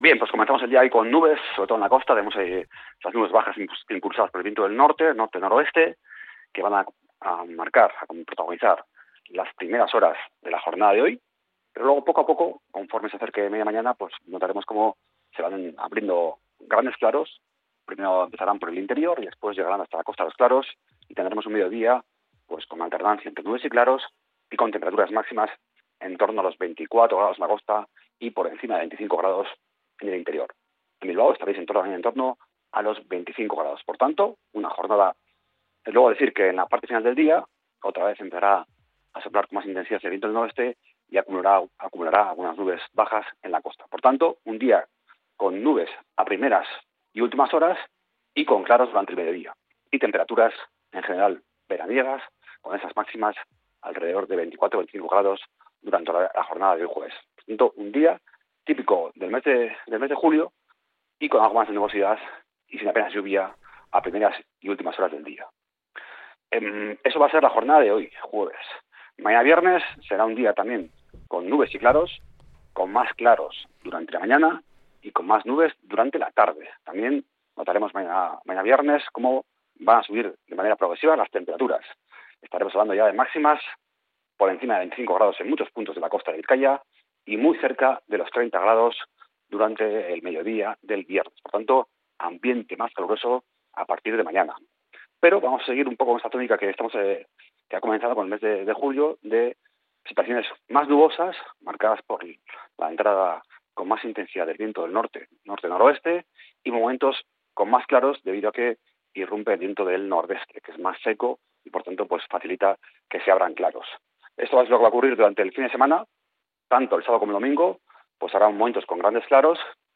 El pronóstico del tiempo para este jueves 3 de julio